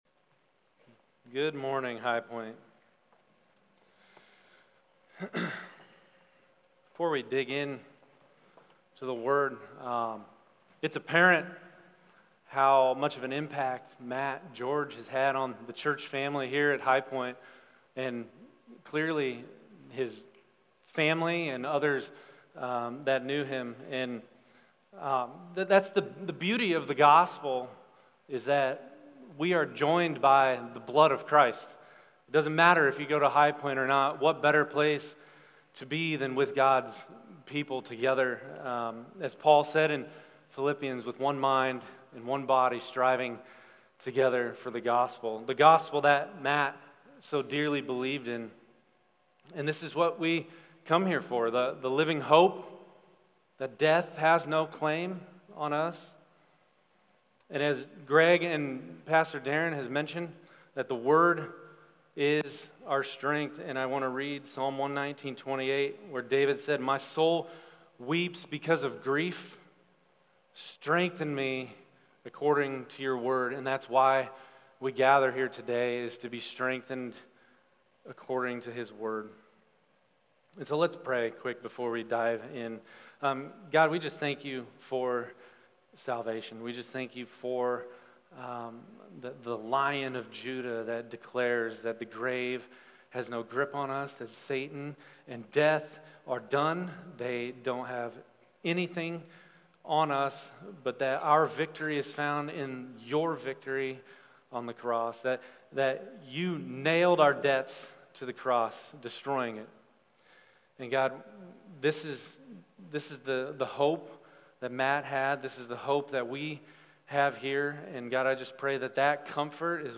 Sermon Questions 1.